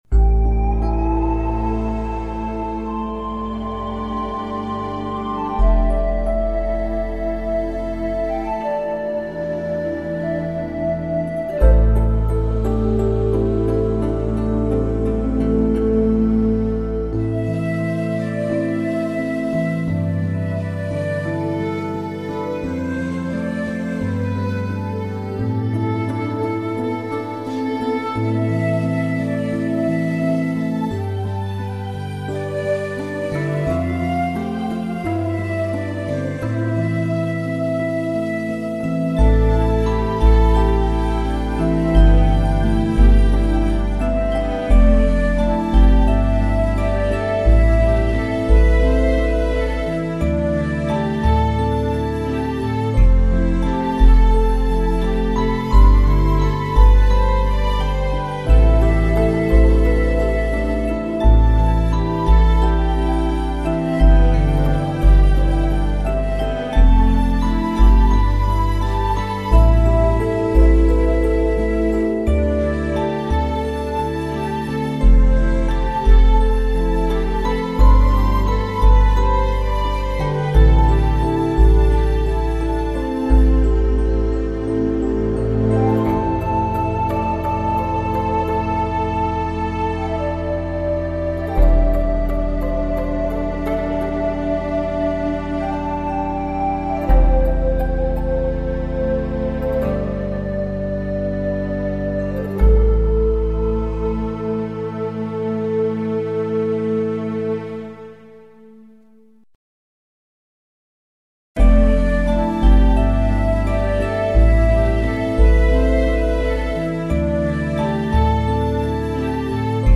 原声音乐
一个带着浓郁中国民间传说之浪漫气息的仙侠故事，几段情致自然的小诗，数曲清灵悠扬的音乐，至今仍让老一辈的玩家难以忘怀。